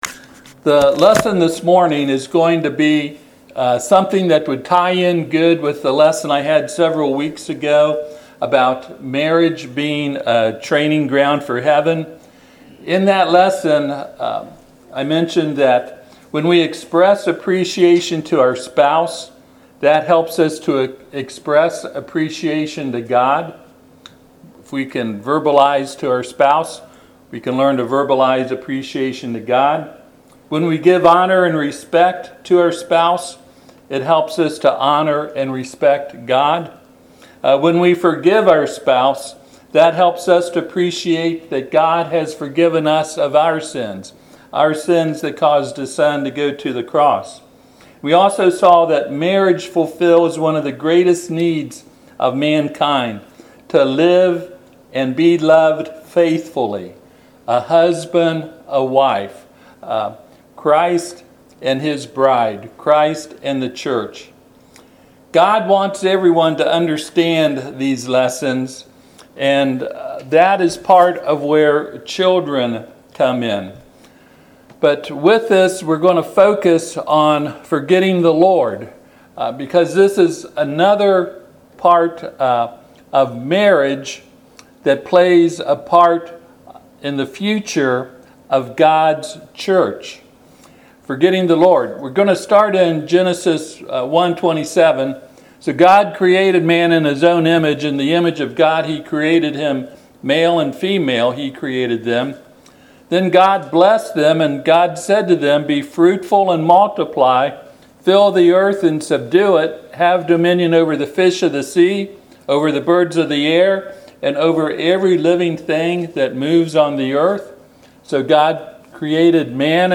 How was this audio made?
Passage: Deuteronomy 6: 4-12 Service Type: Sunday AM